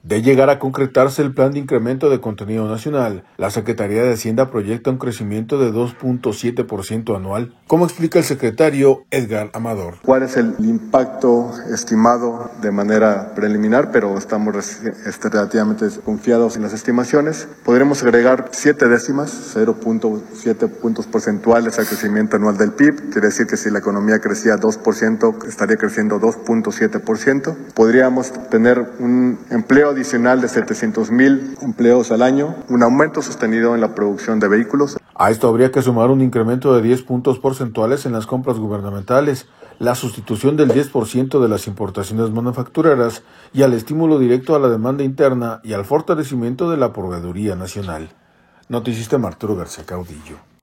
De llegar a concretarse el Plan de incremento de contenido nacional, la Secretaría de Hacienda proyecta un crecimiento de 2.7 por ciento anual, como explica el secretario Edgar Amador.